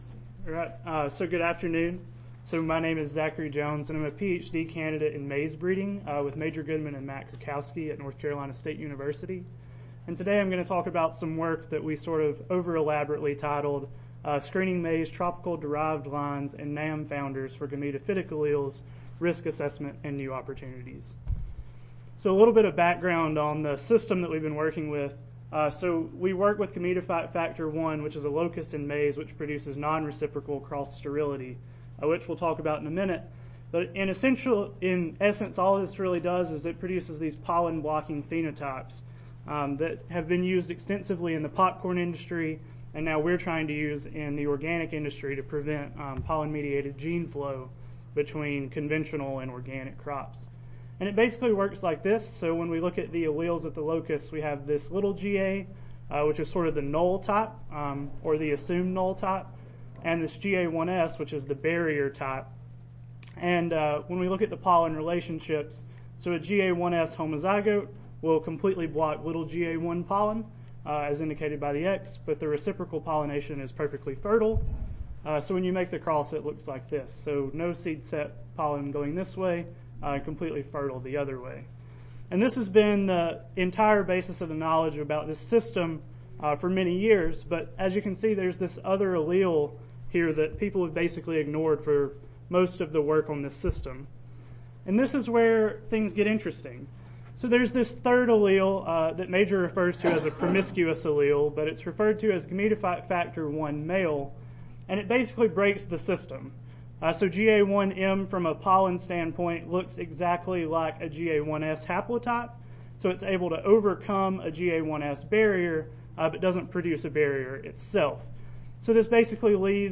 North Carolina State University Audio File Recorded Presentation